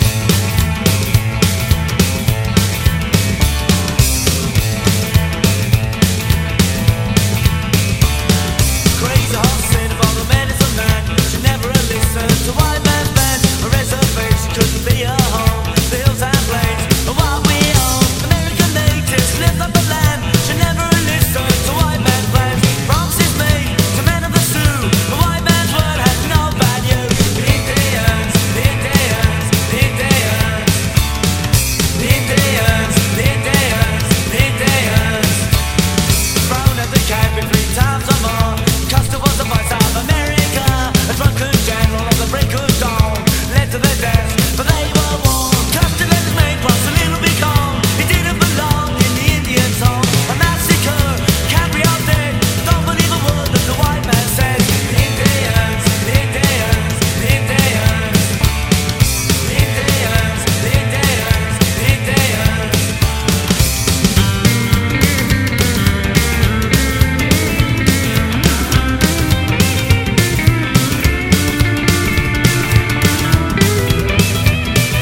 ROCK / 60'S / PSYCHEDELIC ROCK / SOFT PSYCHE
69年USポップ・サイケ！
全体的にはフィリー〜ボストン周辺のサイケ・バンドにも通じる、粗削りながらも魅力溢れる音像が特徴。